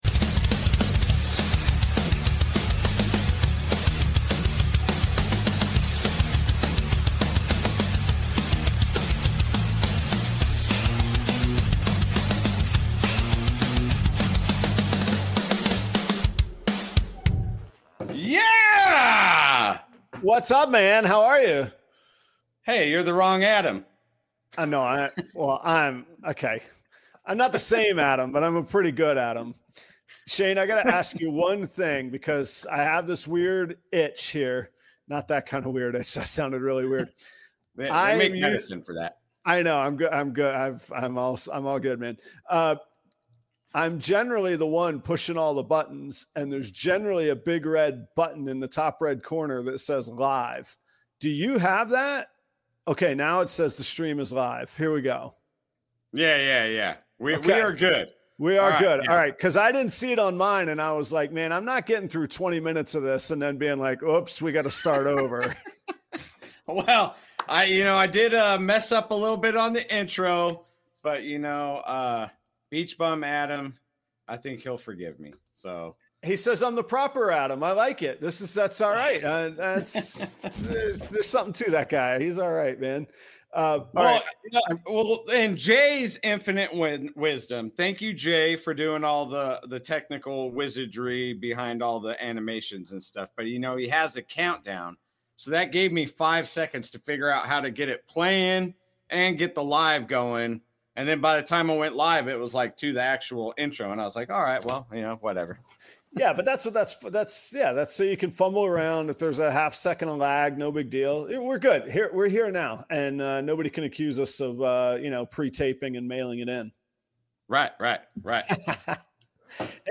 reptile breeder interview